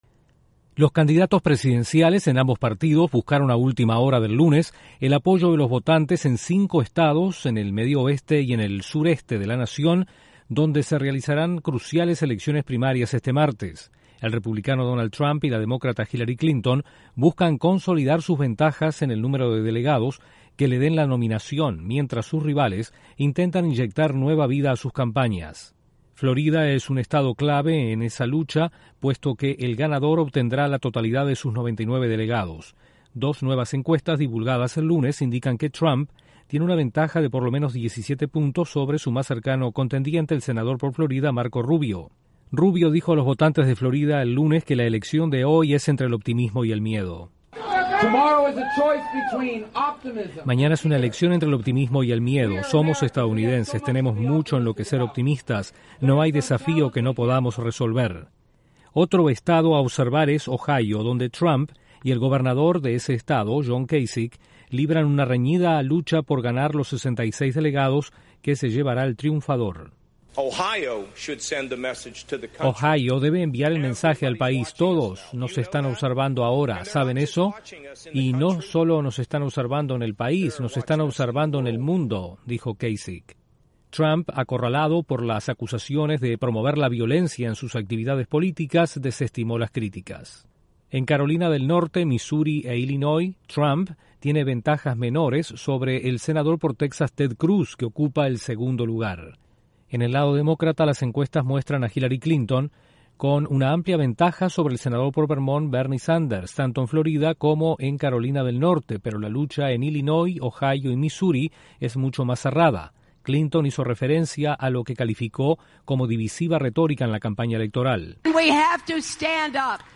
En la campaña presidencial en EE.UU., los republicanos aspirantes a la nominación enfrentan cruciales elecciones este martes. Desde la Voz de América en Washington informa